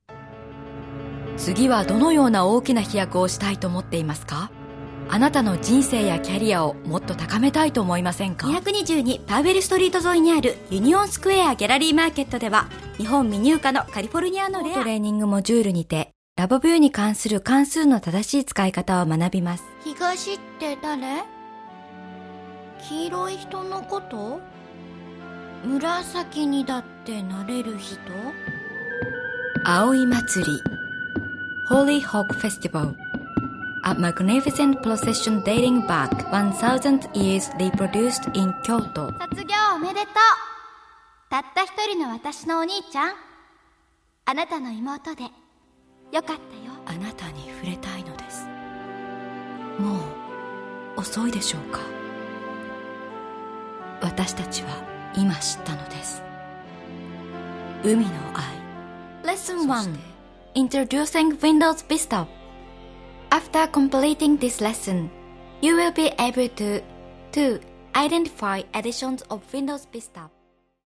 Clear, warm, sincere and friendly Japanese voice with 16 years experiences!
Kein Dialekt
Sprechprobe: Werbung (Muttersprache):